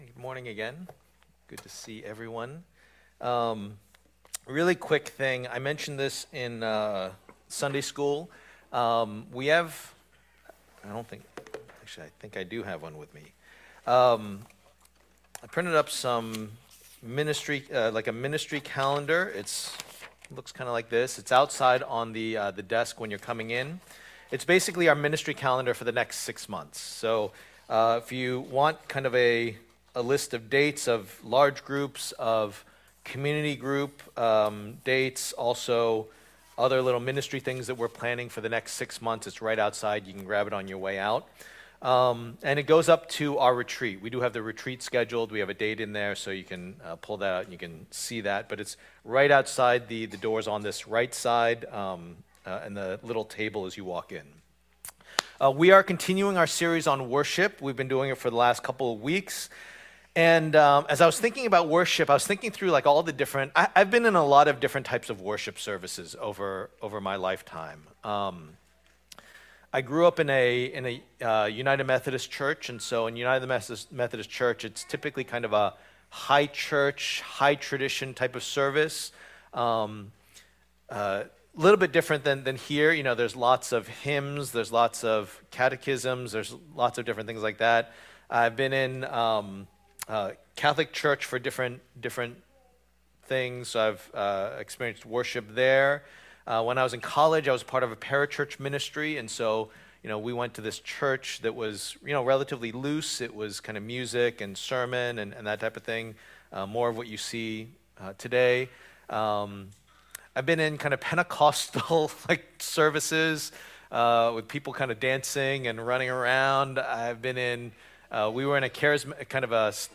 Worship in Spirit and Truth Passage: Psalm 147:1-20 Service Type: Lord's Day « Worship